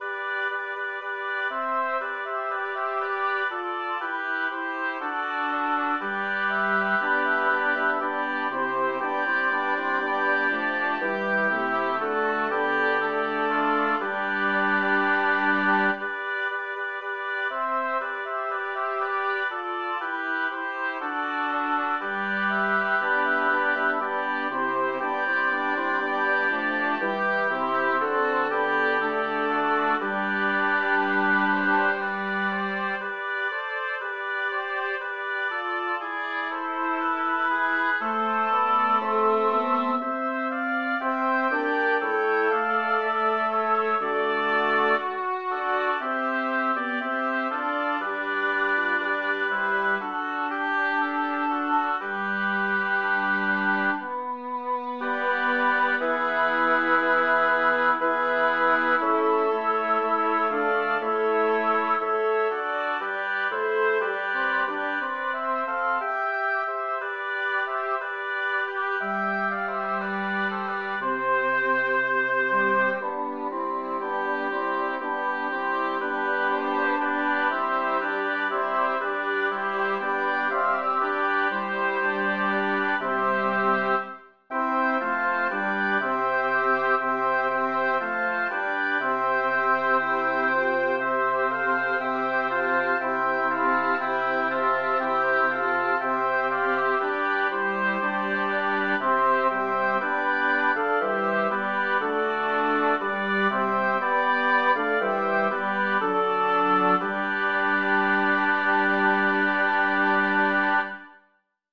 Number of voices: 6vv Voicing: SSATTB Genre: Secular, Madrigal
Language: Italian Instruments: A cappella